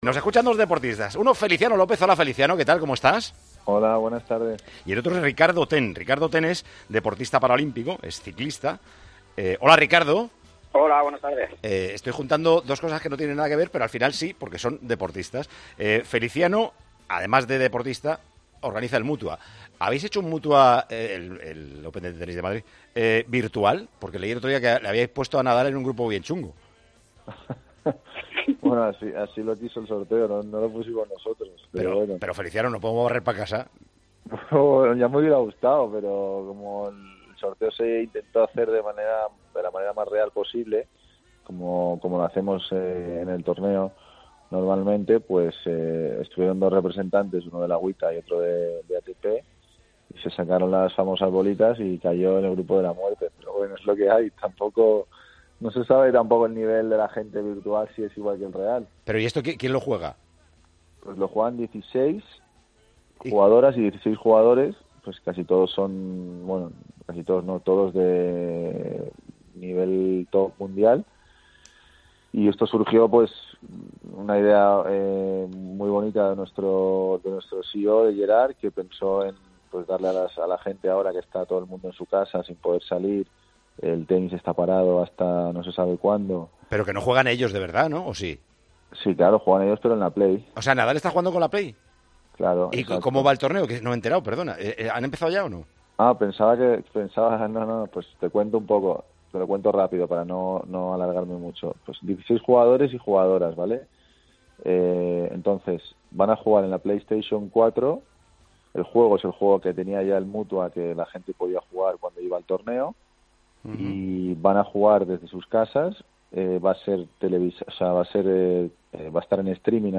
El deportista ha explicado en Tiempo de Juego que la iniciativa permitiría comprar más material para los sanitarios que están luchando frente al COVID-19